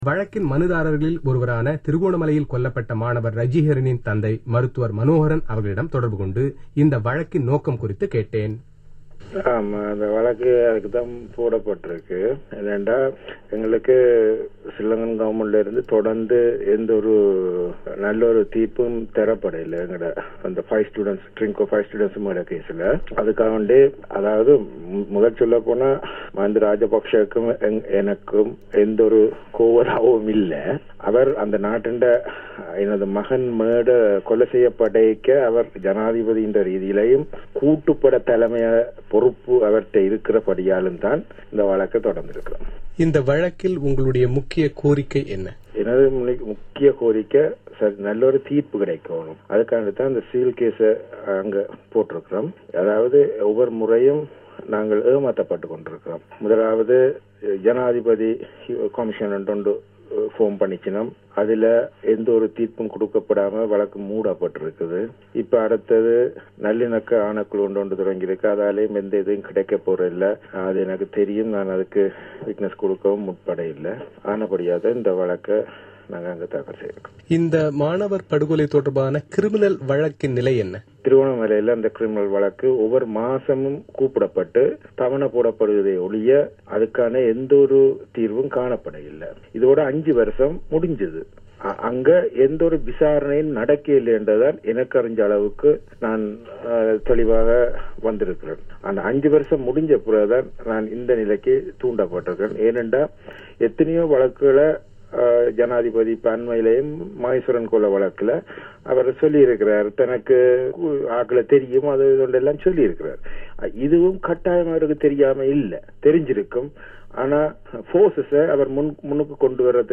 A translation of the interview given in Tamil to the BBC Tamil Service Saturday (1/29/2011) follows: